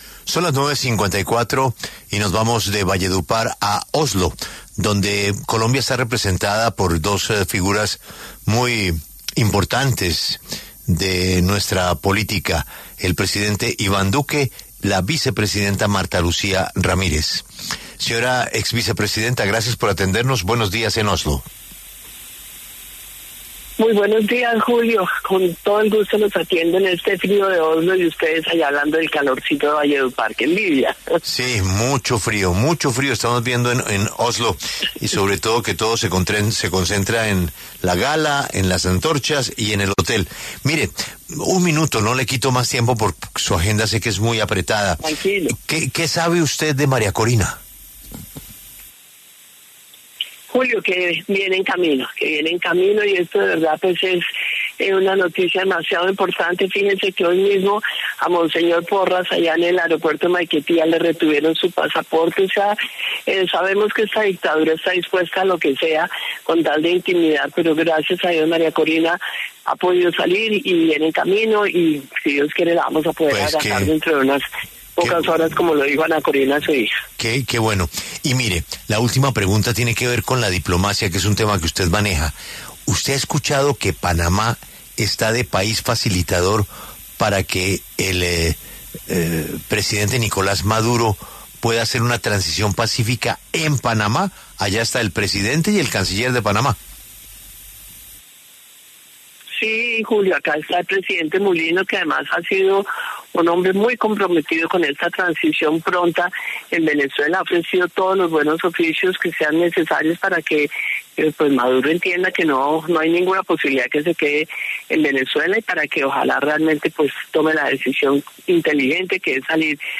Marta Lucía Ramírez habló desde Oslo, Noruega, en donde se realizó la ceremonia de entrega de los premios Nobel.